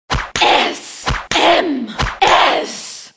» S-M-S плётка Размер: 27 кб